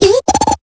Cri de Charpenti dans Pokémon Épée et Bouclier.